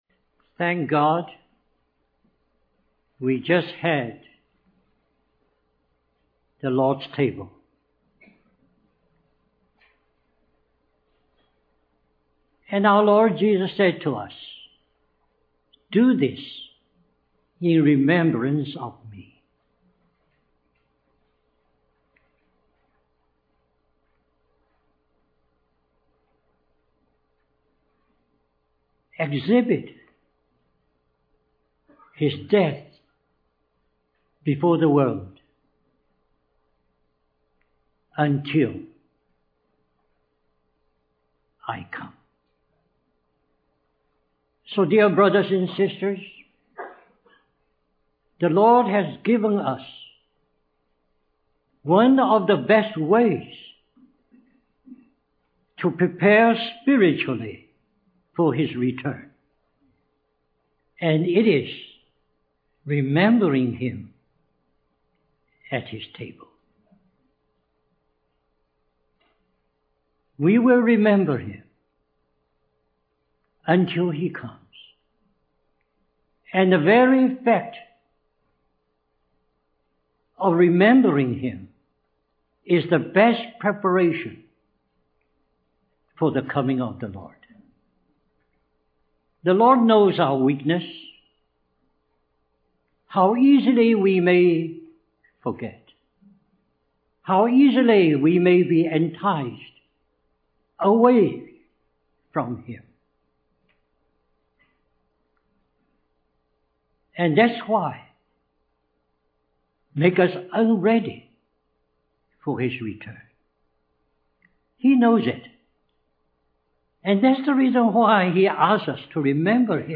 In this sermon, the preacher emphasizes the importance of being prepared for the coming of the Son of Man. He references Matthew chapter 24, where Jesus speaks about the faithful and prudent servant who is entrusted with the responsibility of taking care of his household.